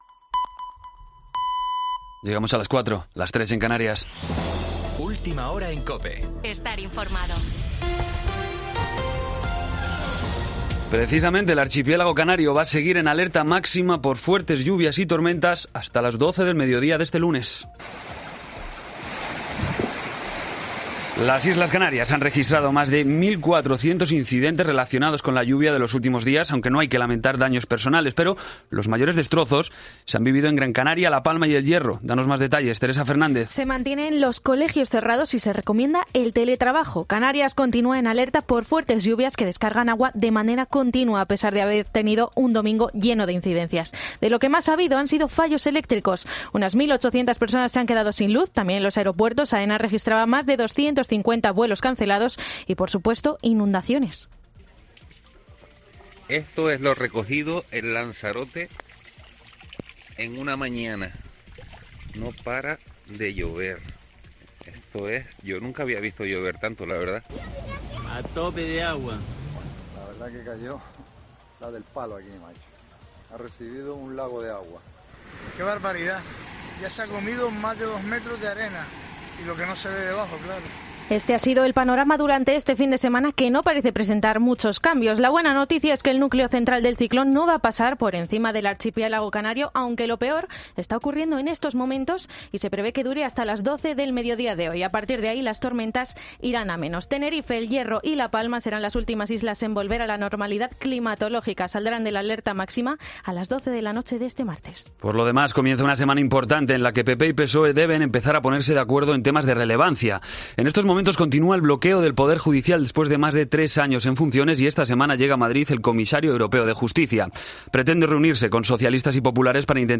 Boletín de noticias COPE del 26 de septiembre a las 04:00 hora